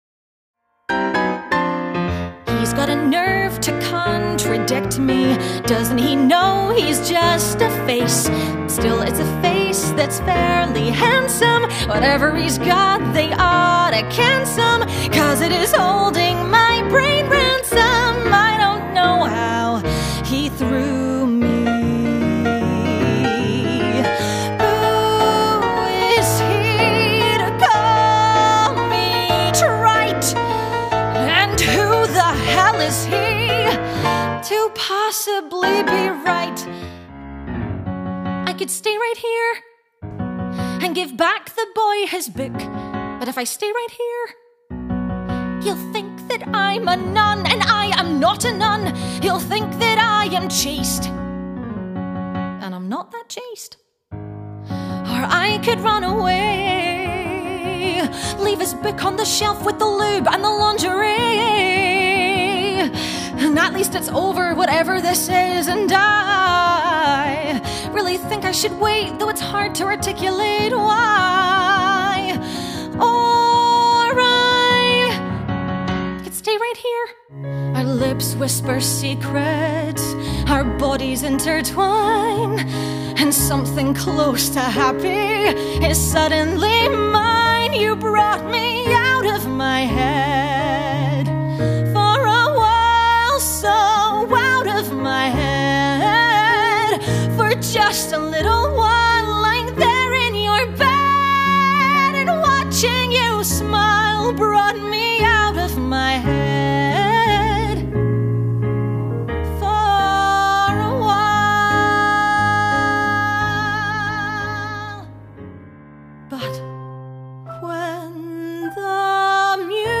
Singing Showreel
Female
Scottish
Bright
Warm
mt-singing-reel.aac